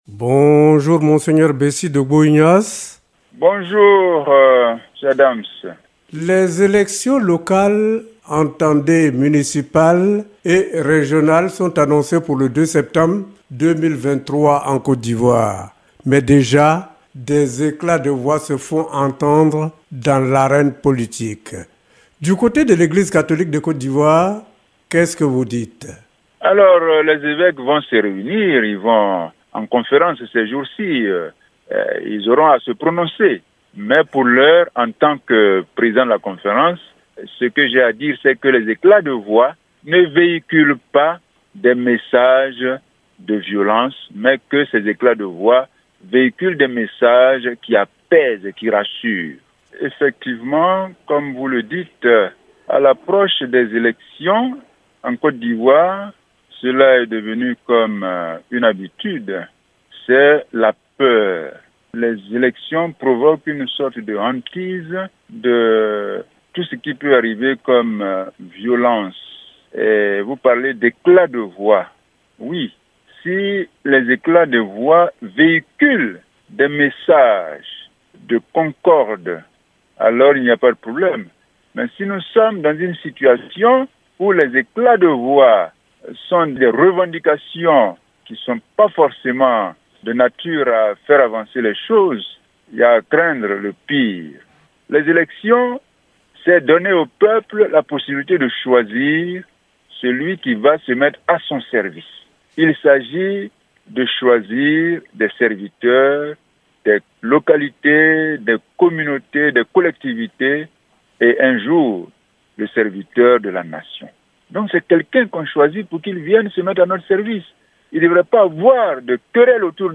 Archevêque de Korhogo et Président de la Conférence des Évêques catholiques de Cote d’Ivoire, Monseigneur Ignace Bessy Dogbo est aujourd’hui l’Invité de la Rédaction de la Radio de la Paix.
invite-de-la-redaction-monseigneur-ignace-bessy-dogbo-president-de-la-conference-des-eveques-de-cote-divoire.mp3